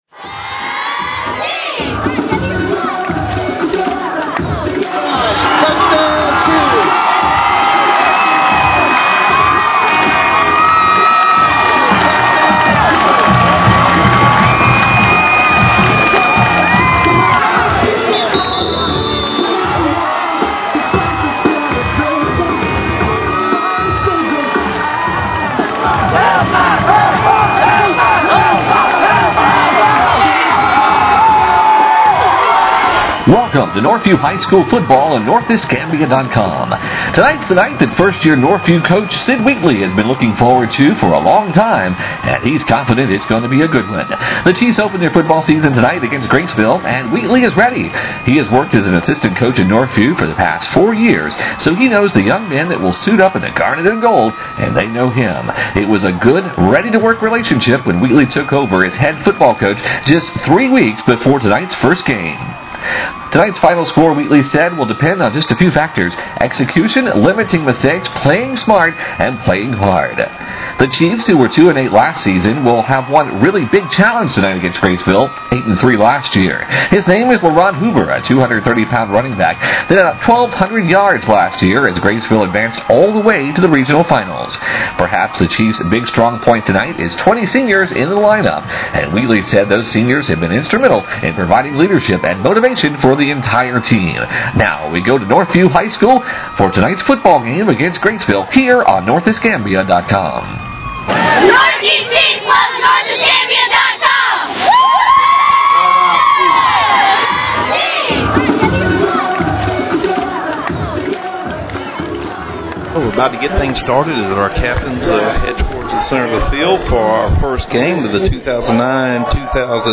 To download the MP3 audio from the September 4 Northview High School game against Graceville, click here.